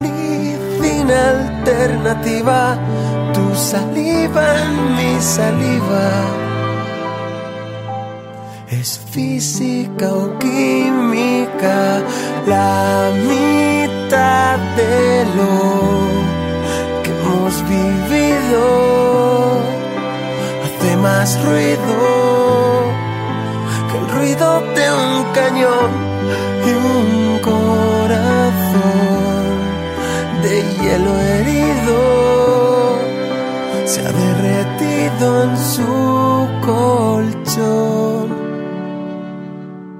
романтические , 2000-х , зарубежные , поп